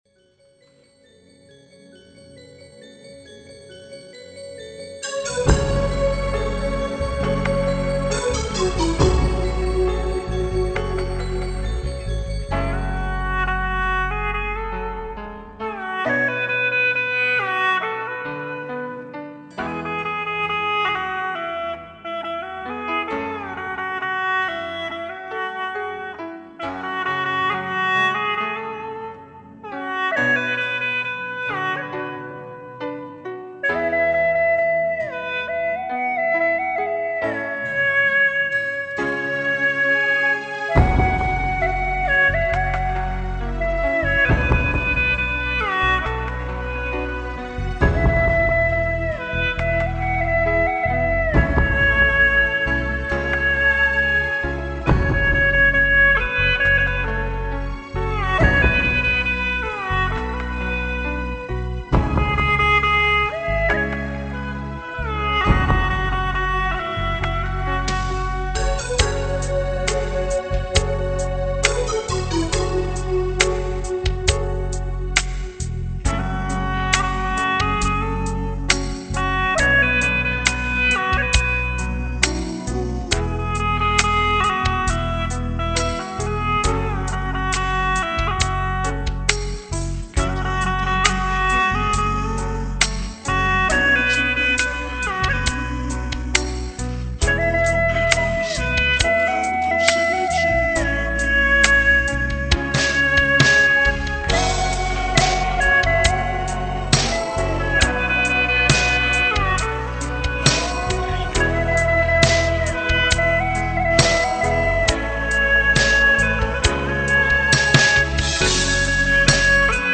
曲类 : 流行